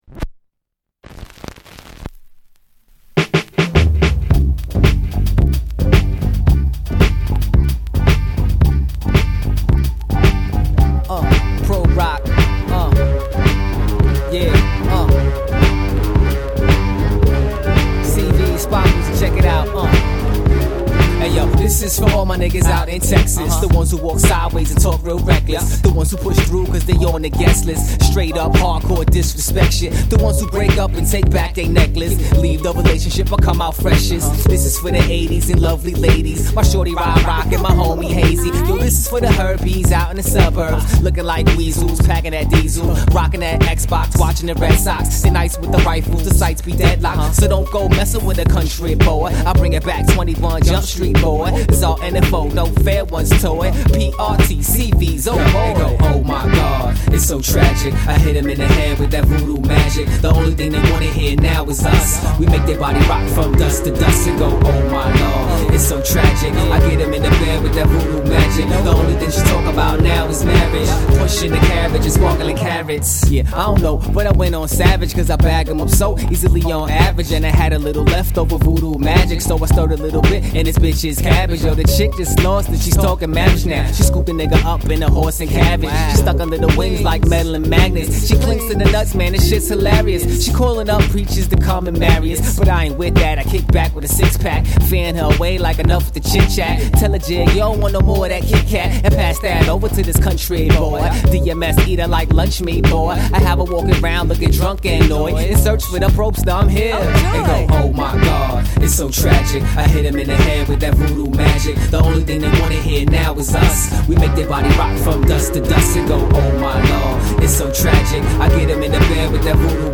Harlem emcee
amped beats and a crazy flow